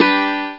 Guitar(g Maj Sound Effect
Download a high-quality guitar(g maj sound effect.
guitar-g-maj.mp3